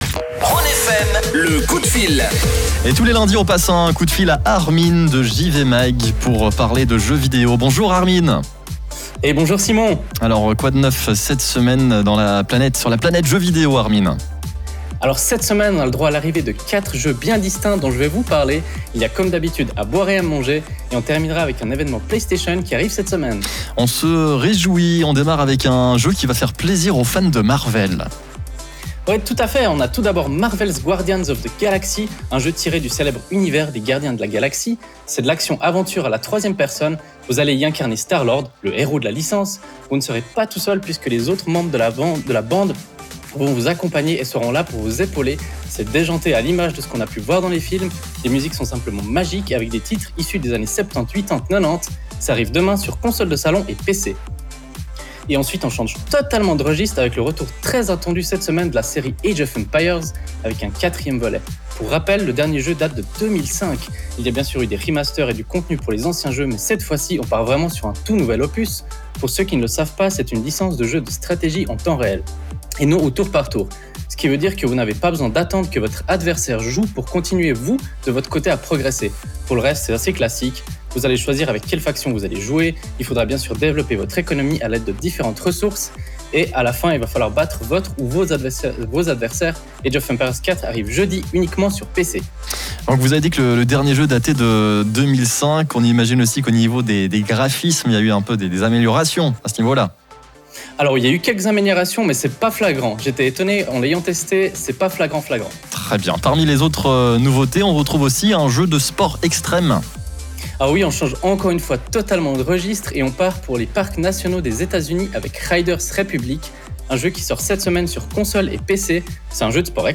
Comme tous les lundis, nous avons la chance de proposer notre chronique gaming sur la radio Rhône FM. C’est l’occasion cette semaine de parler de quatre sorties majeures ainsi que de l’événement Playstation.